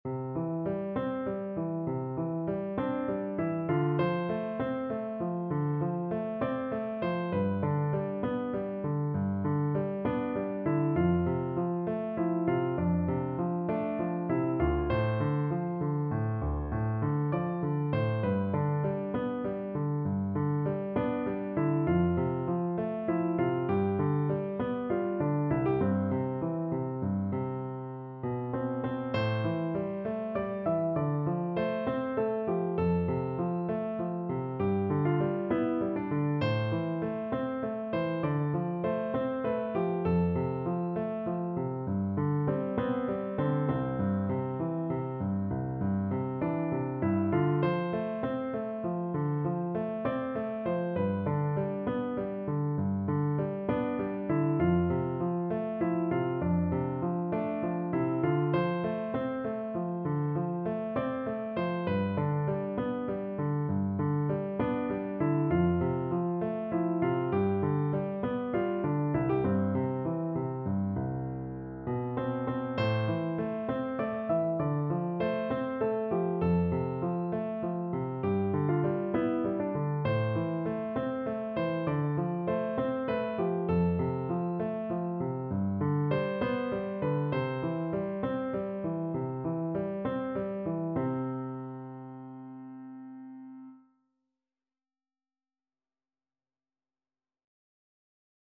No parts available for this pieces as it is for solo piano.
6/8 (View more 6/8 Music)
Piano  (View more Intermediate Piano Music)
Classical (View more Classical Piano Music)